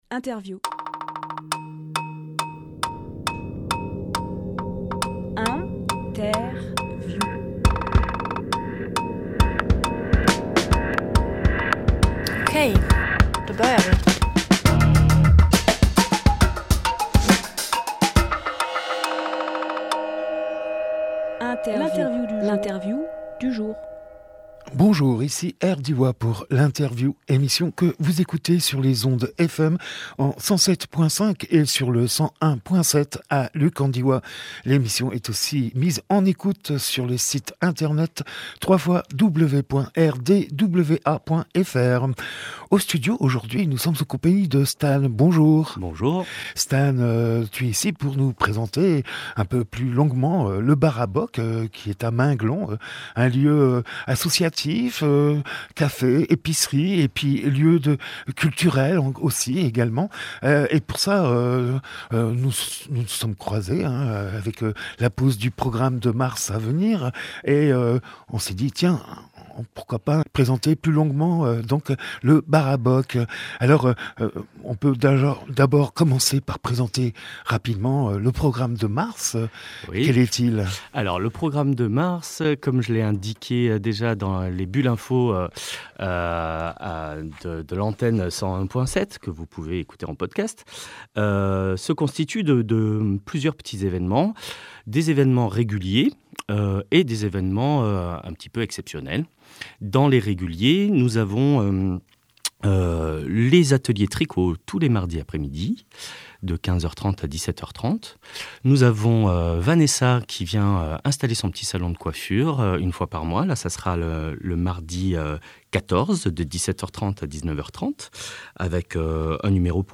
Emission - Interview Le Barabock, lieu culturel Publié le 1 mars 2023 Partager sur…
28.02.23 Lieu : Studio RDWA Durée